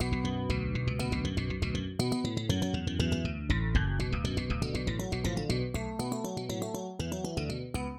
Moonbeam Tonnage 采样的低音吉他层
Tag: 120 bpm Fusion Loops Bass Guitar Loops 1.35 MB wav Key : Unknown